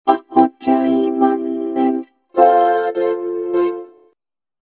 ボコーダーを使う
シンセの音量を大きめにすると、よりハッキリとロボット・ボイスになると思います。
安物のカラオケ・マイクで録りました（何と言ってるか、わかりますか？笑）。
カラオケ・マイクで録ったボコーダー音♪（MP3）
vocoder.mp3